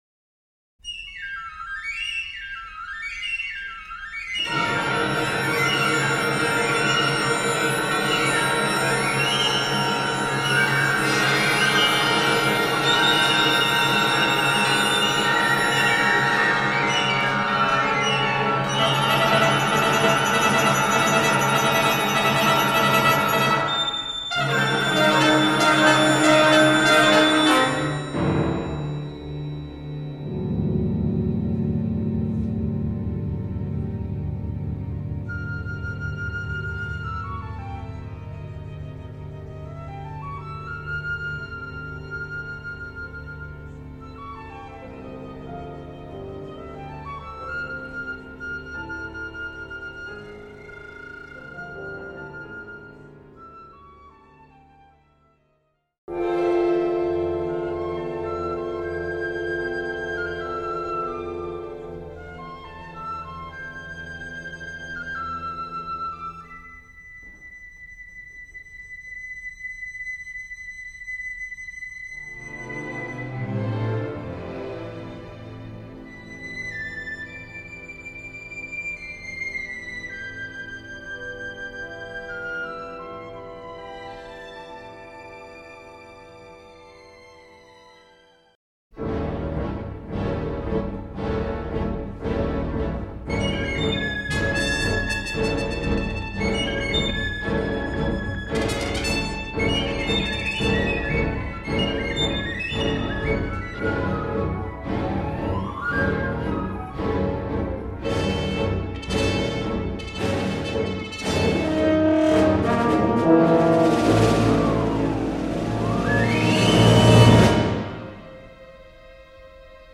Voicing: Piccolo Solo